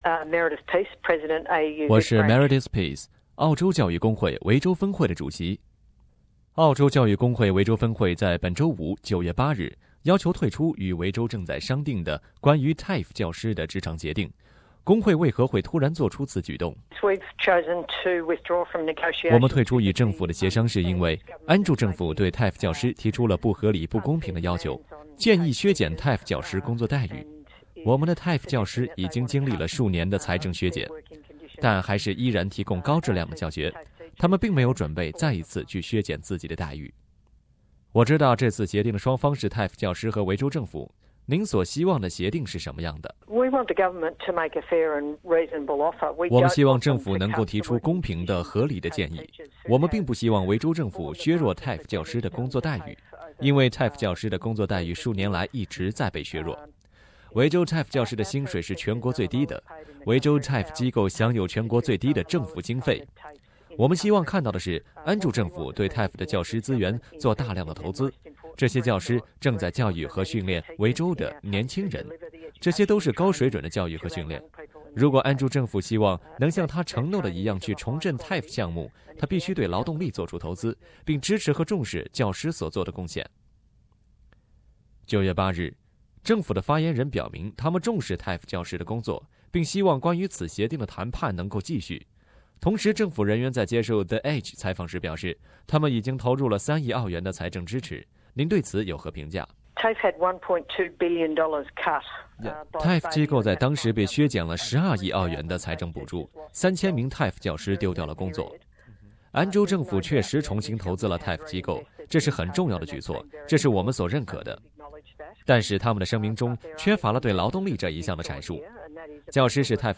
（以上仅为嘉宾个人观点，不代表本台立场。）